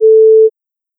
hangup.wav